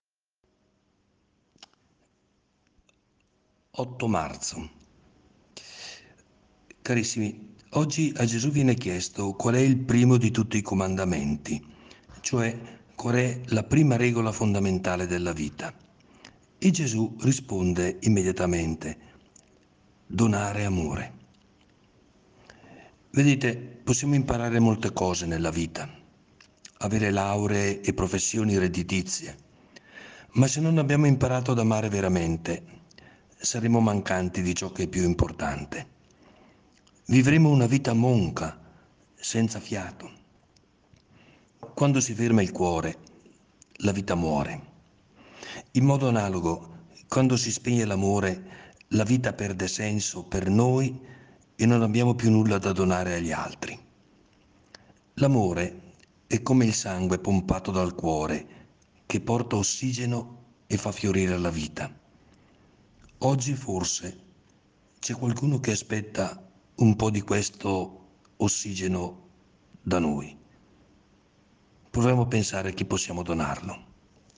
In questi 40 giorni il Vescovo commenterà la Parola di Dio per trarne ispirazione per la giornata.